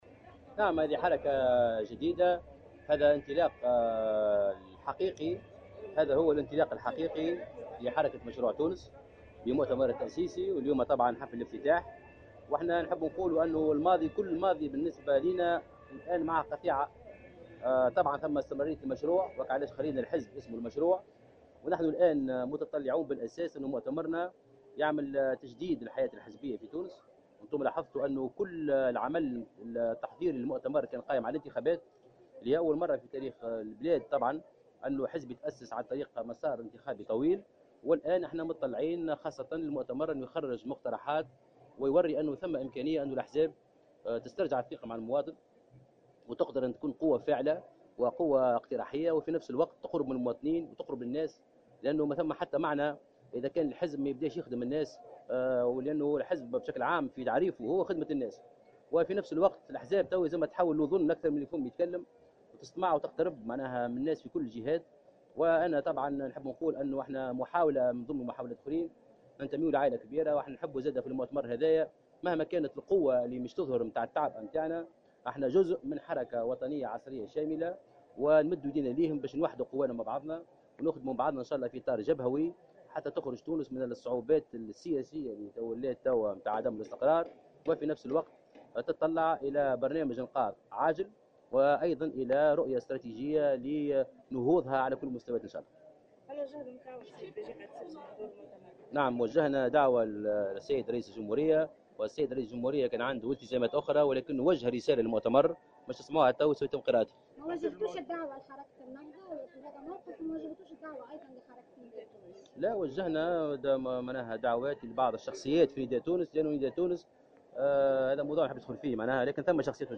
وقال في تصريح لـ "الجوهرة أف أم" إنه يتطلع إلى تجديد الحياة الحزبية في تونس ويحاول الانتماء إلى "عائلة كبيرة"، معتبرا أنه لأول مرة في تاريخ تونس يتم تأسيس حزب من منطلق مسار انتخابي.